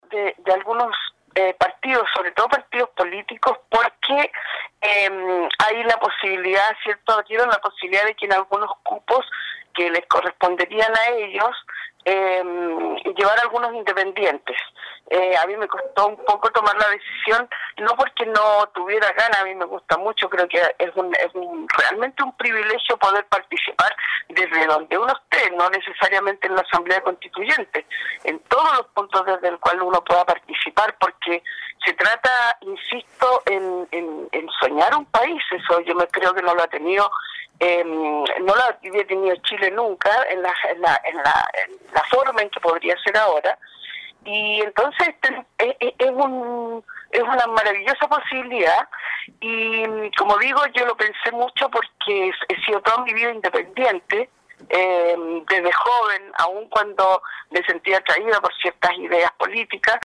En entrevista con radio Estrella del Mar indicó que fue invitada a participar de este proceso por una nueva Constitución por el partido Liberal